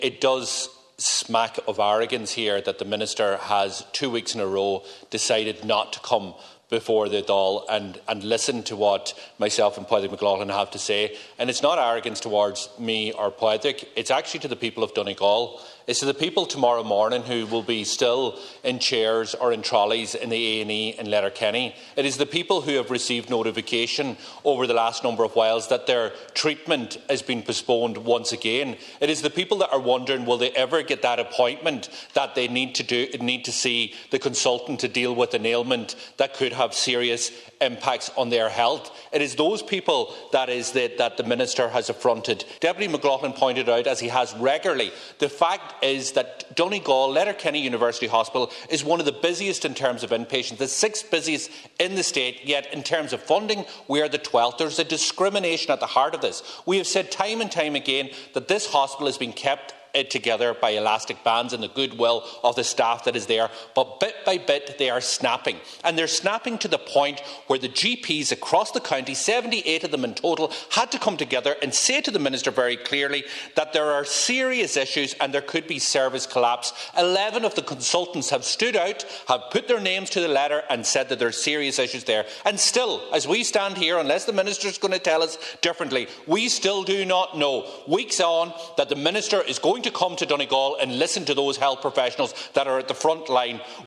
Tensions were high in the Dáil last night after Health Minister Stephen Donnelley failed to be in attendance for the second time for the Topical Issue on the need for an external review at Letterkenny University Hospital.
Meanwhile Deputy Doherty says it’s the patients who are suffering as these problems persist: